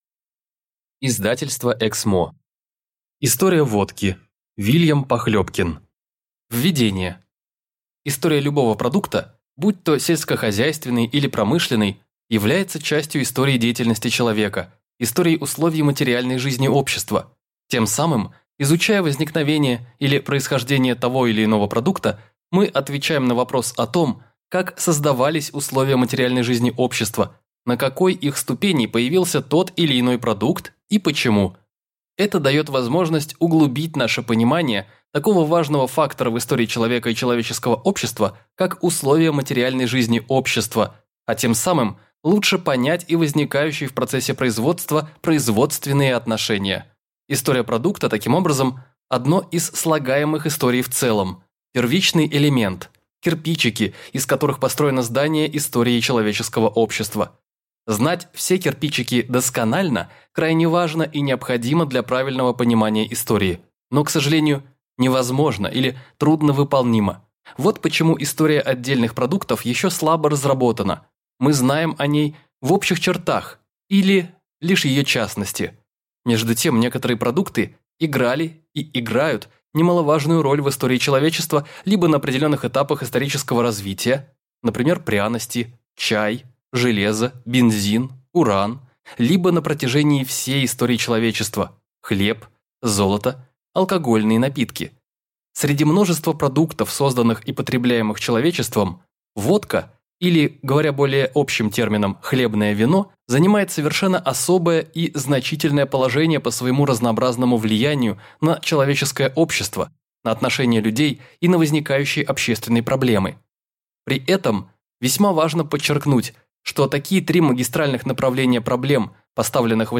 Аудиокнига История водки | Библиотека аудиокниг